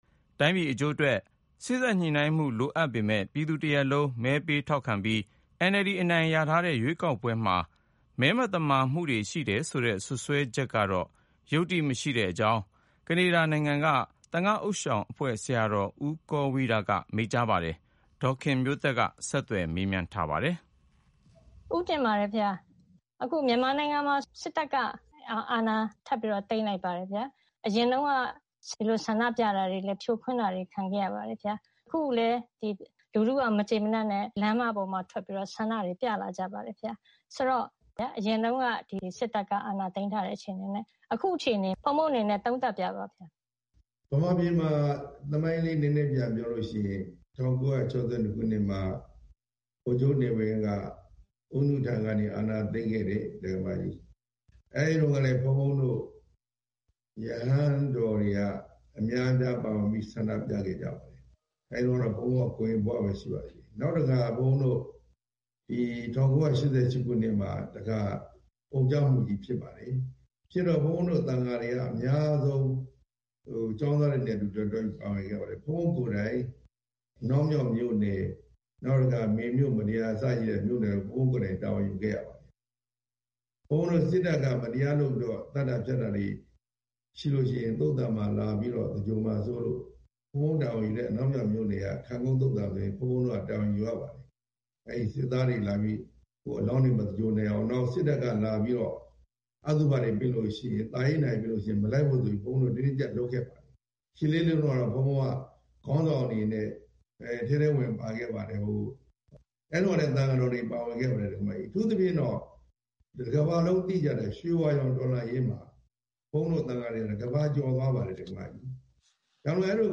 VOA အင်တာဗျူး